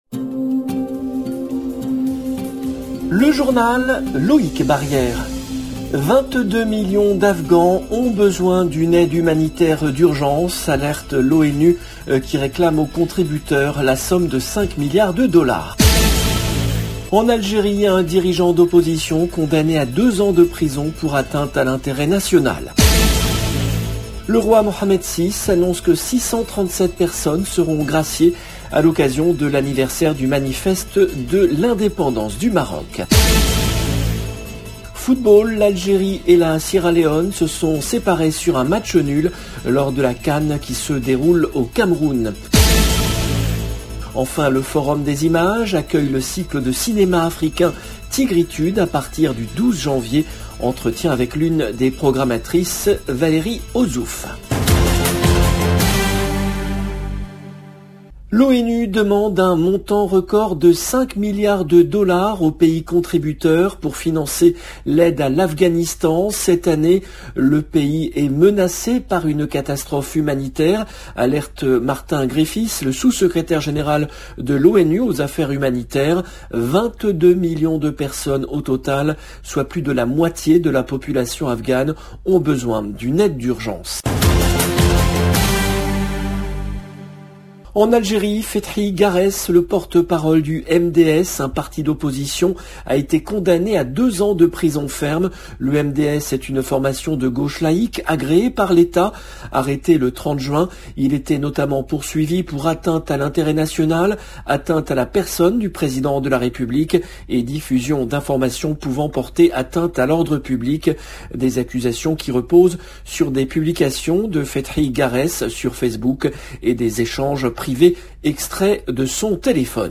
Enfin, le Forum des Images accueille le cycle de cinéma africain Tigritudes à partir du 12 janvier. Entretien avec l’une des programmatrices